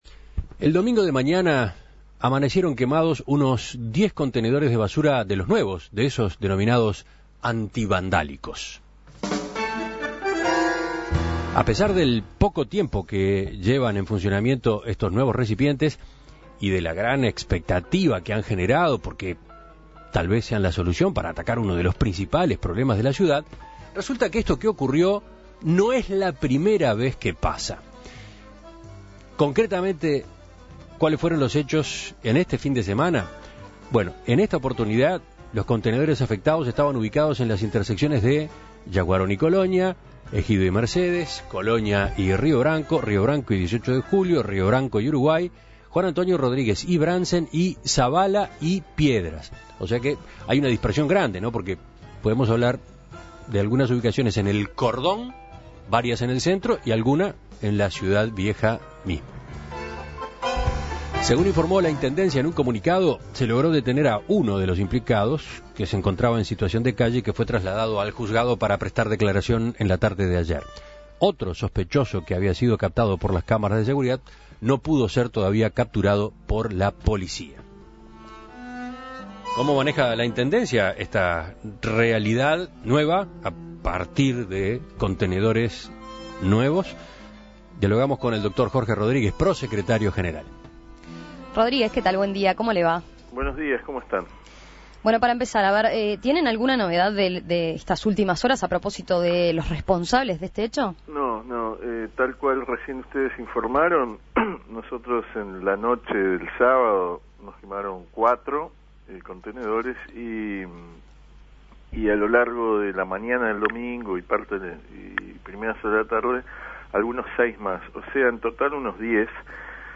Este fin de semana se constataron actos vandálicos contra los nuevos contenedores instalados por la Intendencia. En total se contabilizaron 10 contenedores incendiados en las zonas del Centro, Cordón y Ciudad Vieja. Para entender cómo analizan esta incidencia desde la comuna, En Perspectiva entrevistó a Jorge Rodríguez, pro secretario general.